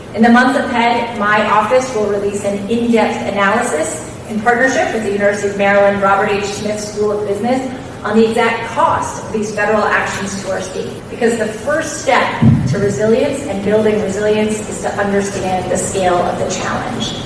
Maryland State Comptroller Brooke Lierman, along with State Attorney General Anthony Brown were guests at a town hall in Towson where they discussed ways the state is dealing with how actions of the Trump Administration are affecting Marylanders. Lierman said her office will be working on a study to find out the financial implications for the state…